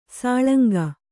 ♪ sāḷanga